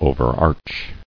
[o·ver·arch]